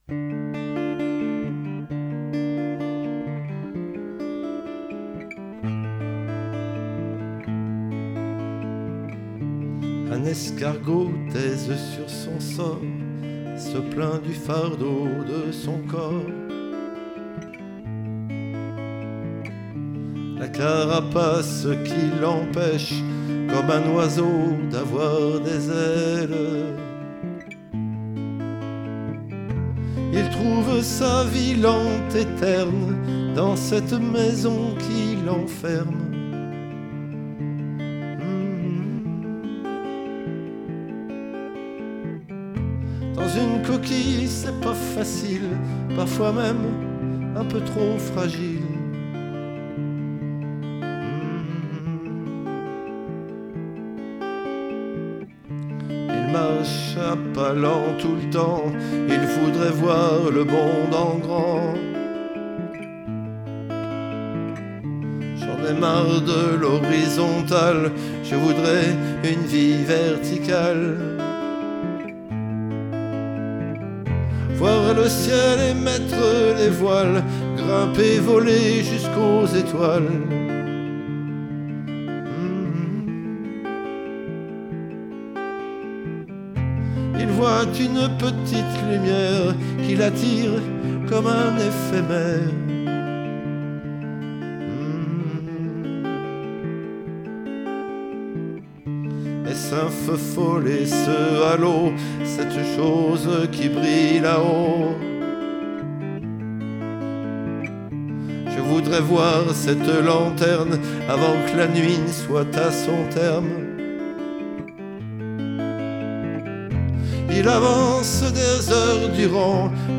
chant, guitare